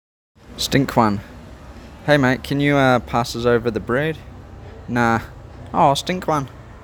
Here are 21 popular slang terms with an audio playlist from a Kiwi (person, not bird).